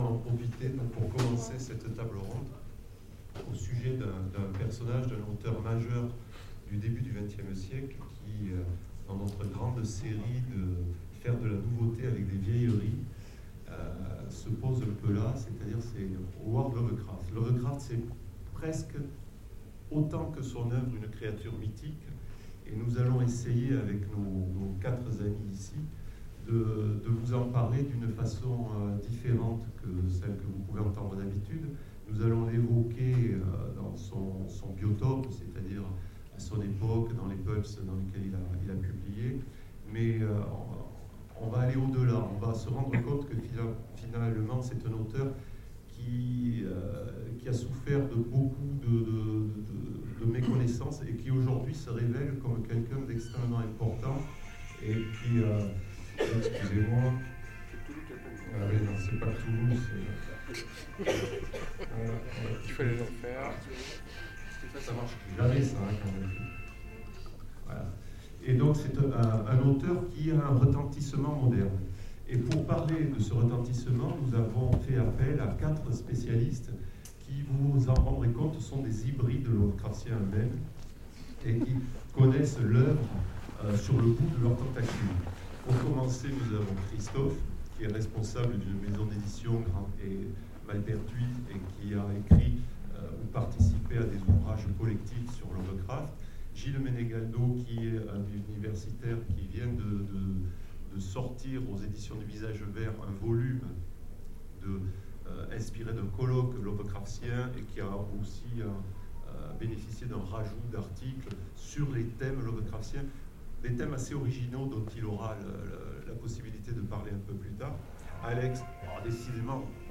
Rencontres de l'Imaginaire 2017: Conférence l'influence de Lovecraft 80 ans après
sevres_2017_conference_lovecraft_ok2.mp3